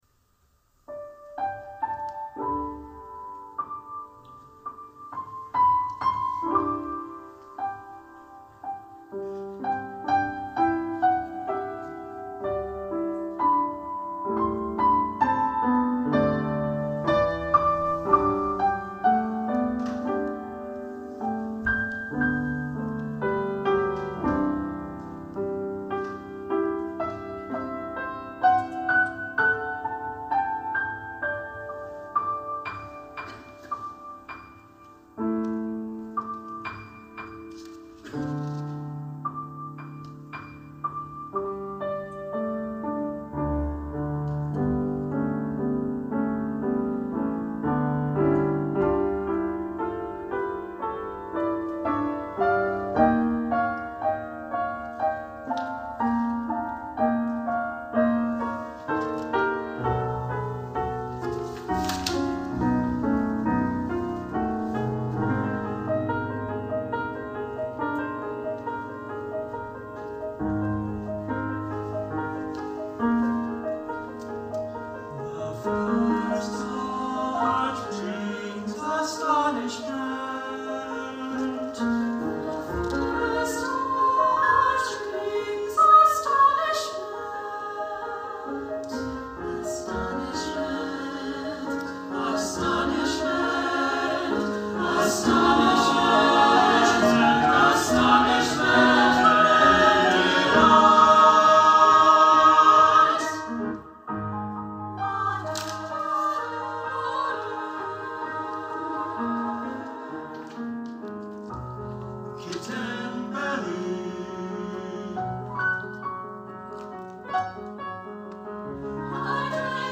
SATB and piano version